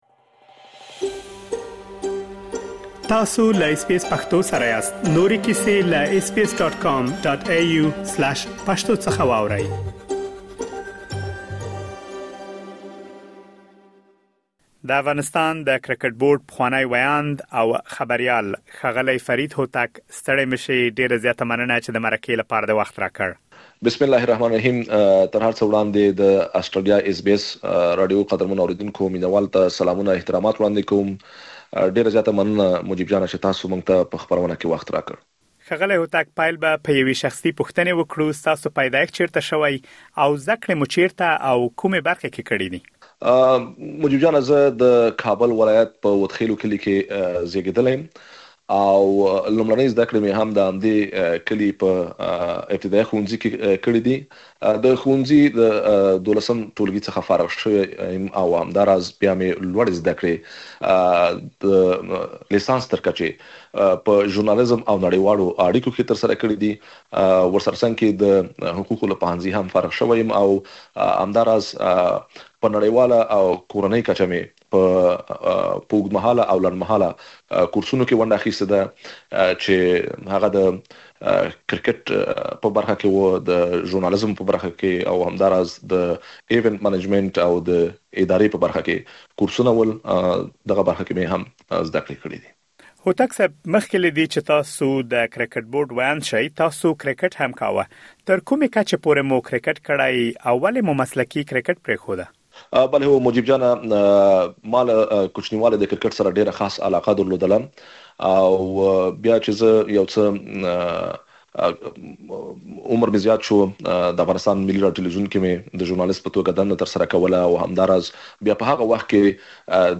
مرکه ترسره کړې